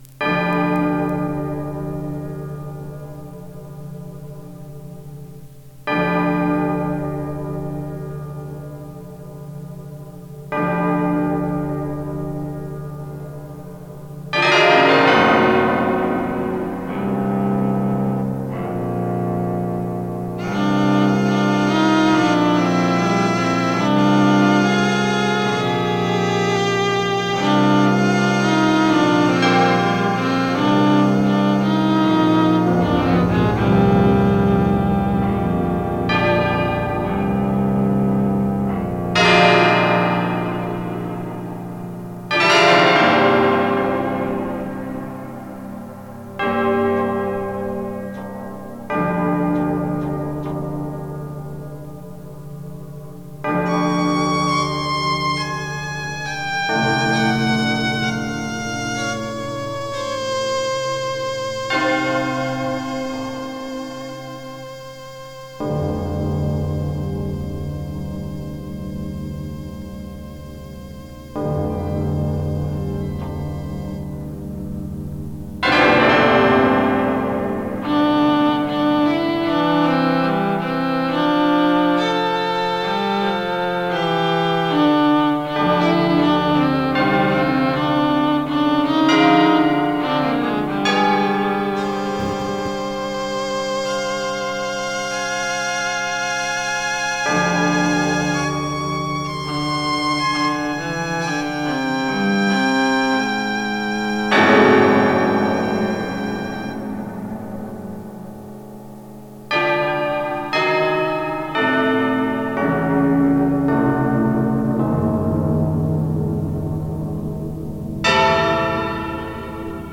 Voice(s) and ensemble
performed with electronic accompaniment
Archival Recordings (original synthesized version)